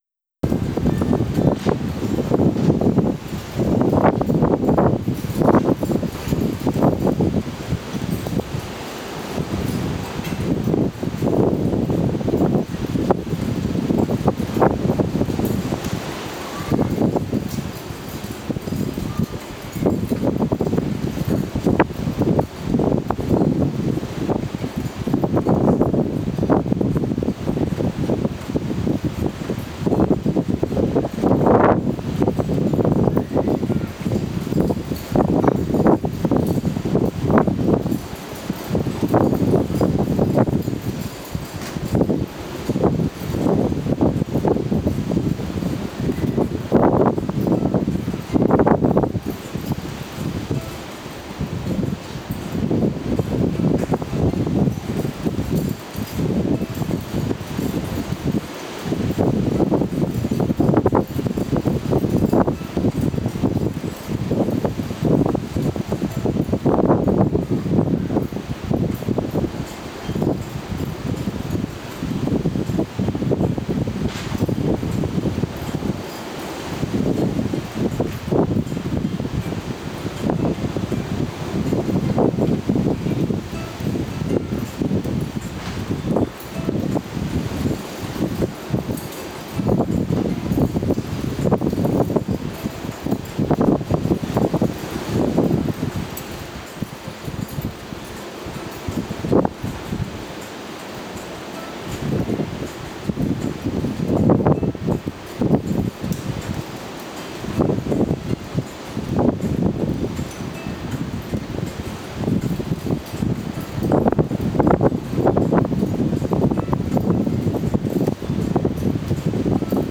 Bangkok - Koh Tao (Fieldrecording Audio, Foto, Video)
Original Video Ton: aufgenommen durch Abspielen der Videos im VLC Player und Aufnehmen mit Audacity (16Bit/44kHz)